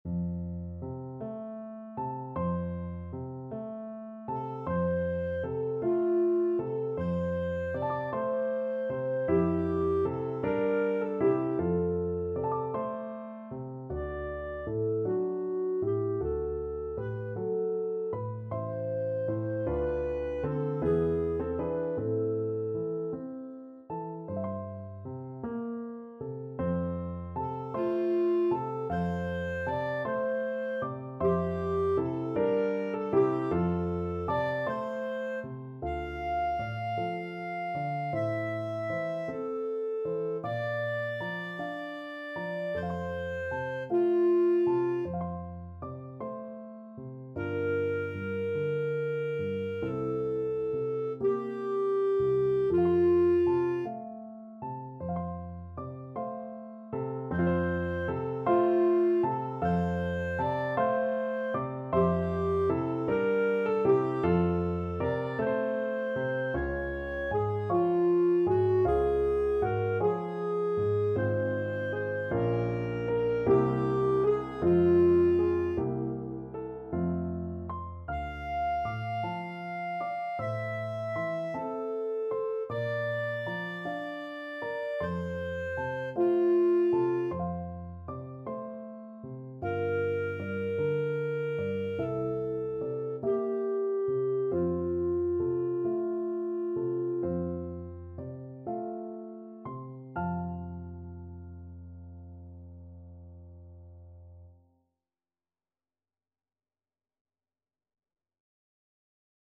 ~. = 52 Allegretto
6/8 (View more 6/8 Music)
Classical (View more Classical Clarinet Music)